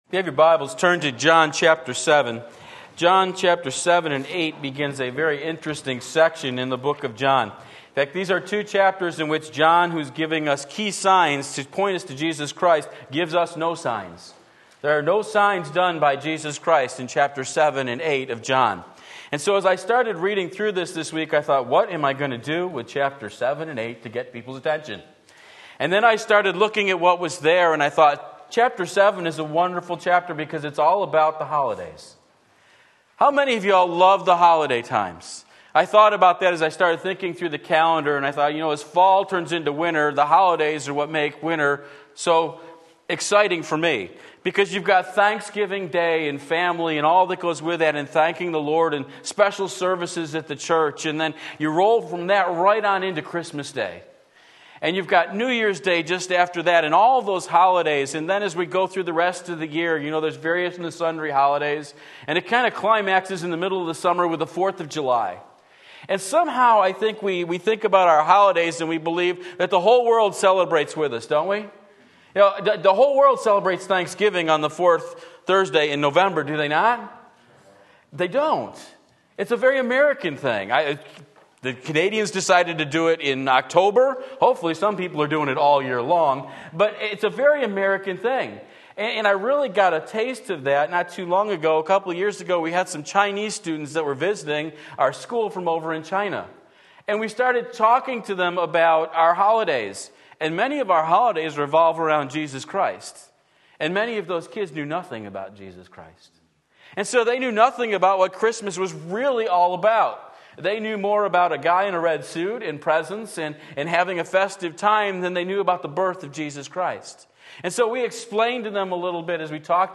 Sermon Link
Reactions to the Truth John 7:1-52 Sunday Morning Service, February 19, 2017 Believe and Live!